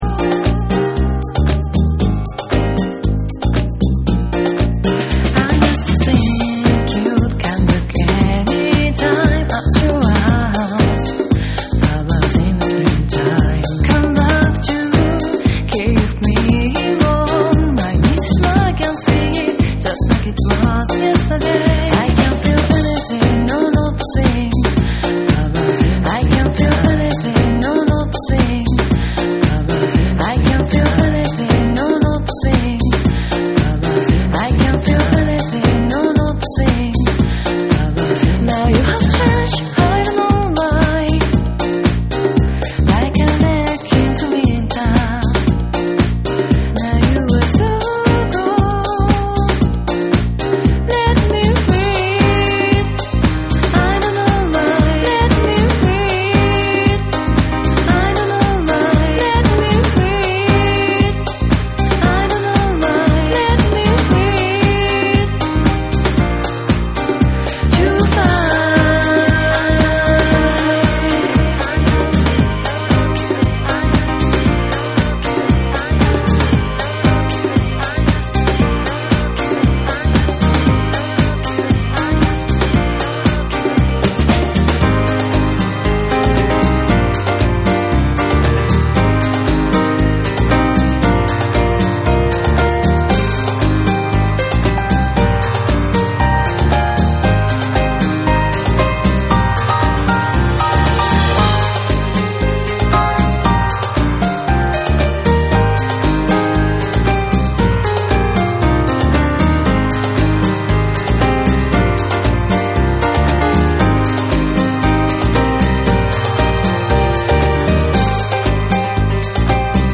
TECHNO & HOUSE / BREAKBEATS / JAPANESE